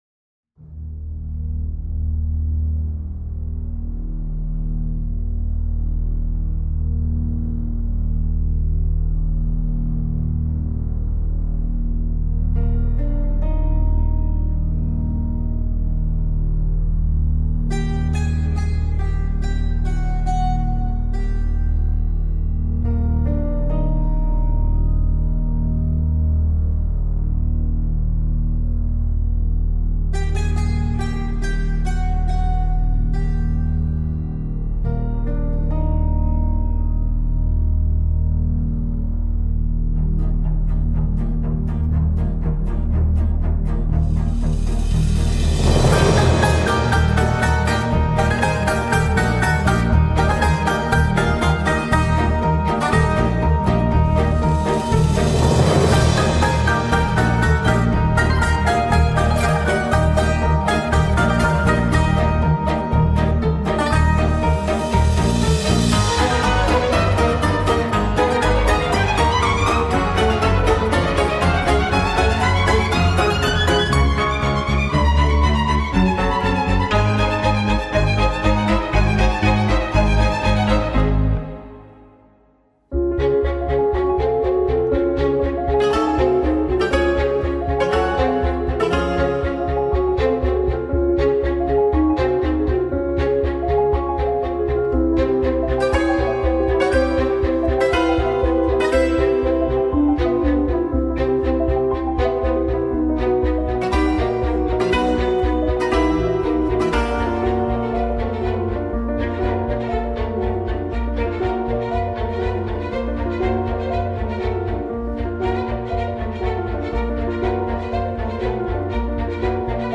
Rafraichissant et pertinent.